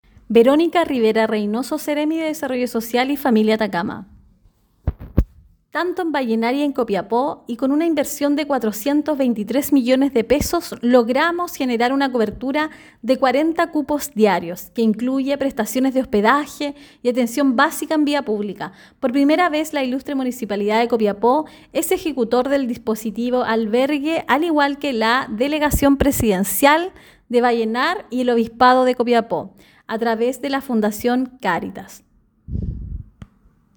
Durante la ceremonia, realizada en la Sala de Cámara Municipal de Copiapó, la seremi de Desarrollo Social y Familia, Verónica Rivera Reynoso, destacó la importancia del trabajo colaborativo con el INE para asegurar la inclusión de este grupo vulnerable en el proceso censal.
Seremi-Desarrrollo-Personas-Situacion-Calle.mp3